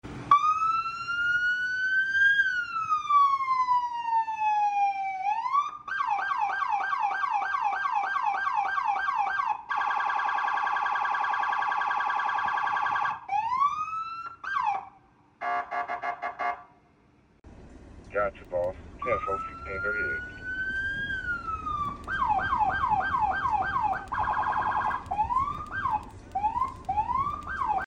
Birmingham PD’s Federal Signal Pathfinder Siren Demo